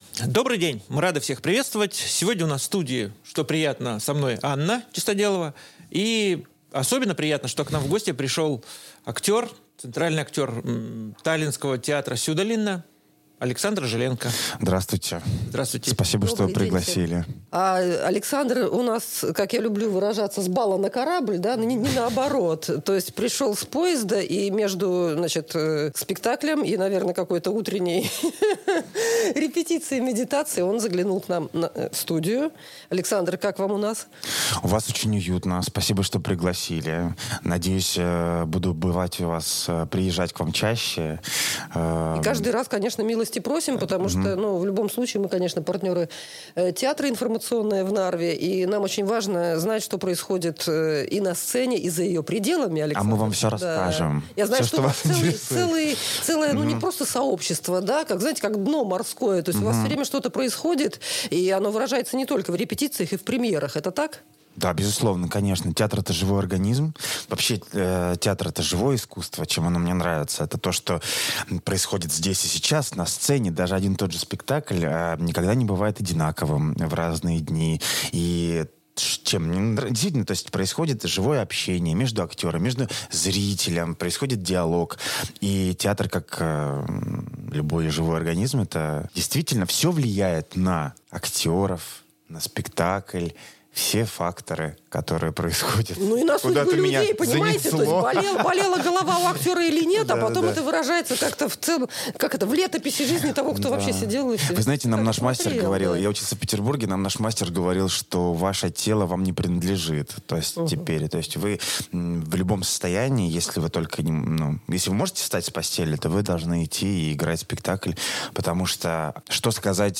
заглянул в студию Radio Narva